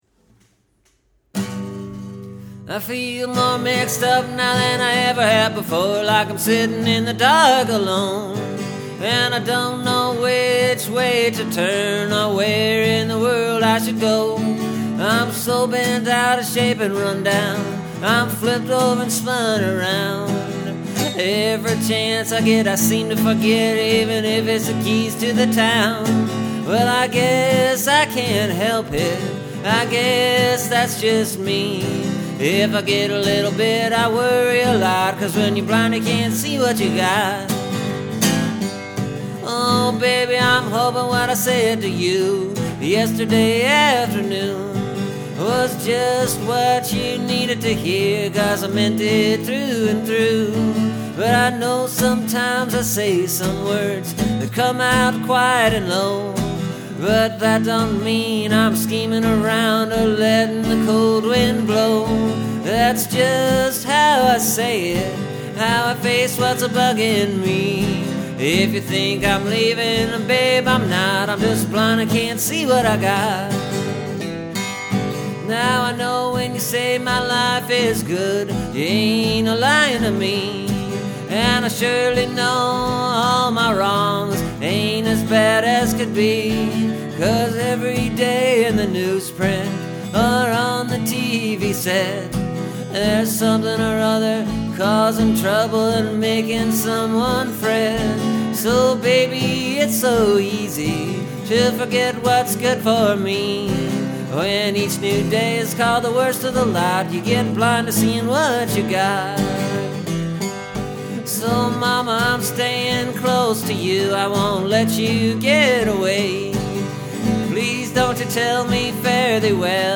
A lot of chord changes. A lot of singing.
And I think I pulled it off fairly well, at least okay enough for having a sore throat all week long.